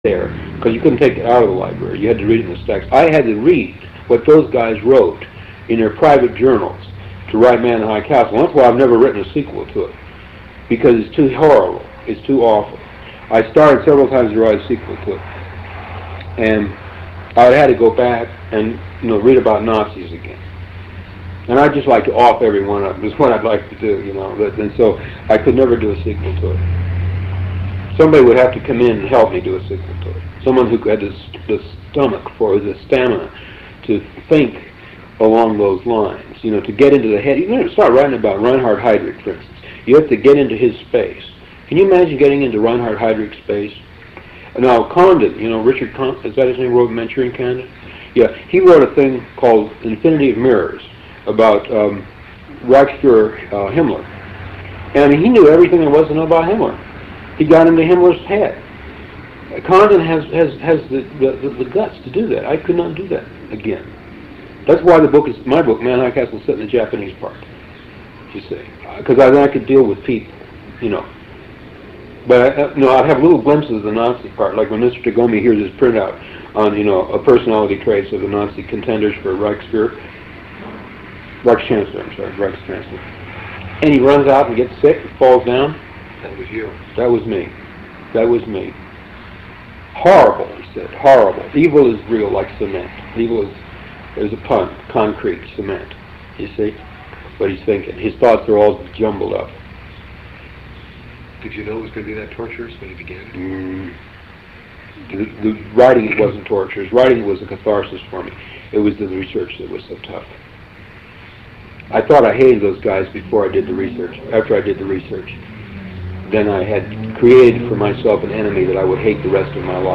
Interview with Philip K Dick 4